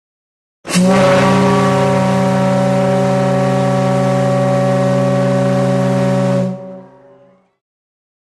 Le sirene del TITANIC: clicca sull'immagine per ascoltare il loro suono.
Potevano per far udire automaticamente il loro suono, della durata dagli 8 ai 10 secondi, una volta al minuto, in caso di nebbia, per informare le altre navi della presenza del TITANIC. Il loro suono, a tre toni, era realizzato grazie alle tre camere acustiche di 23, 38 e 30 centimetri di diametro, e furono udibili ad una distanza di una ventina di chilometri!
Sirena.mp3